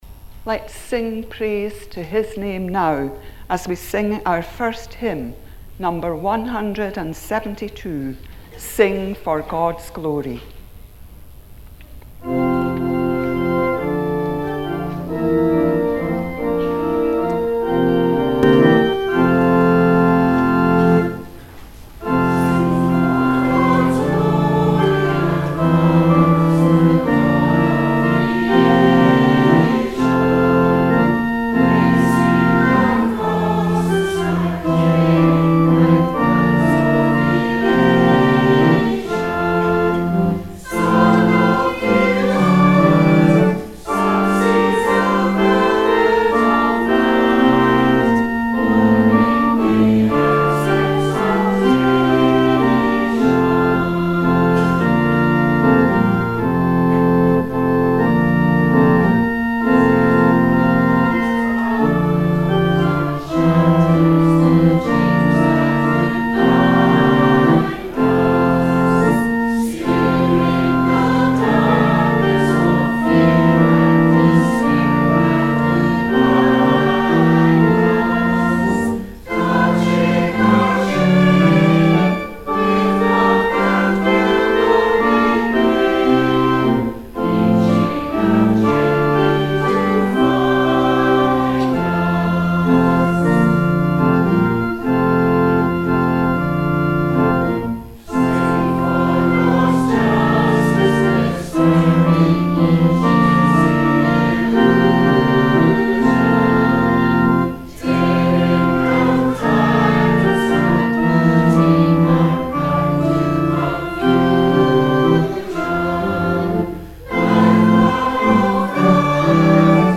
After our Call to Worship we sang